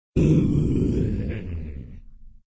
SZombieAlarm.ogg